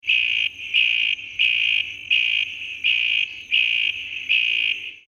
Texas Toad - Anaxyrus speciosus